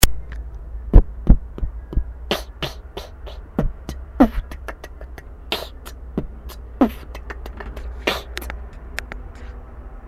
B-b-b(еффект эха)-KCH-kch-kch(Эффект эха)-B-t-pf/tktk-t-Kch-t/B-t-Pf/tktk-t-Kch-t...
тебе бы скорости быстрых хай-хетов) для того они и быстрые)
ну и собстно четкость остольных звуков) кик чего т не так как хотелось бы)
та я бы сделал чётче еслиб сестра не спала я в её комнате битовал)))